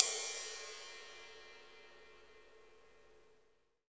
Index of /90_sSampleCDs/Roland L-CDX-01/CYM_Rides 1/CYM_Ride Modules
CYM ROCK 05R.wav